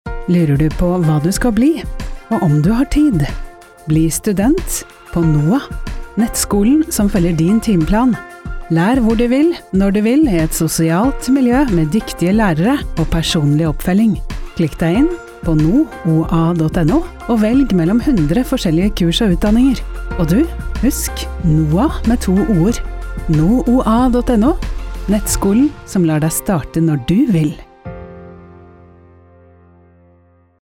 NooAs annonser i Radio Grenland.
Kvinnestemmen til annonser i Radio Grenland
NooA-nettskole-2019-Kvinne-radio.mp3